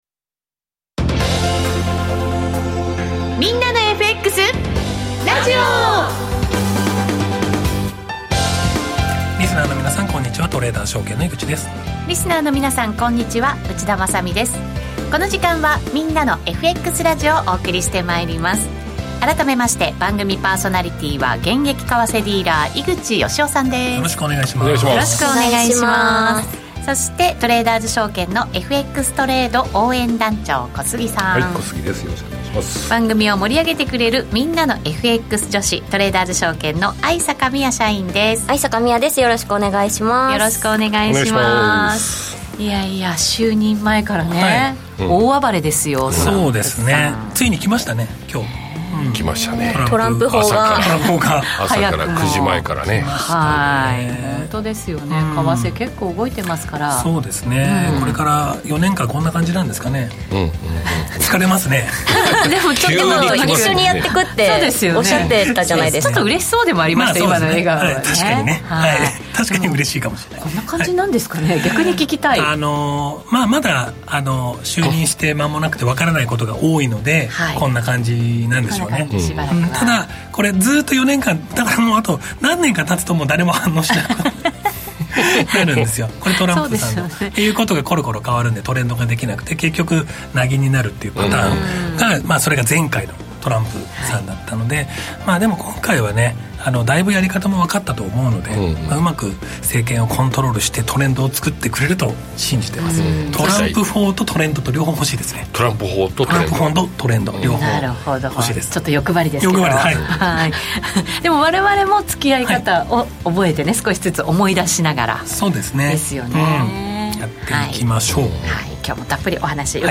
毎週多彩なゲストとの相場談義も必聴！みんなのFXラジオはリスナー参加型ラジオです。